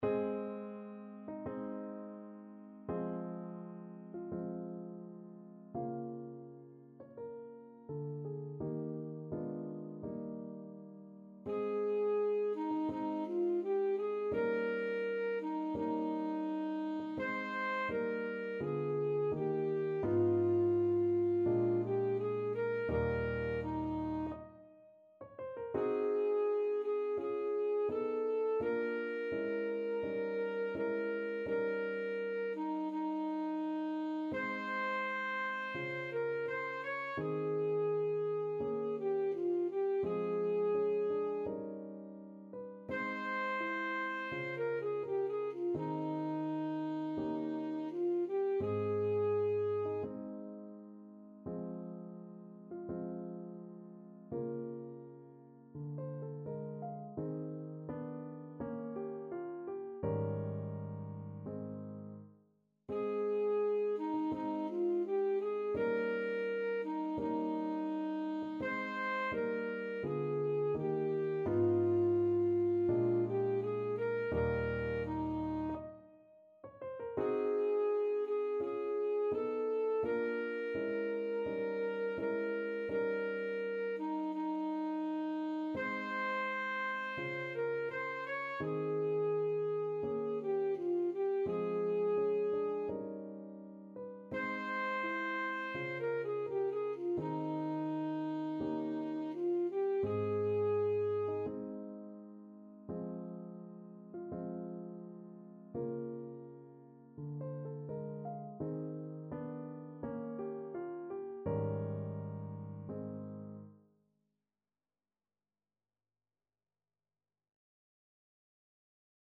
Alto Saxophone
Ab major (Sounding Pitch) F major (Alto Saxophone in Eb) (View more Ab major Music for Saxophone )
2/4 (View more 2/4 Music)
~ = 42 Sehr langsam
Classical (View more Classical Saxophone Music)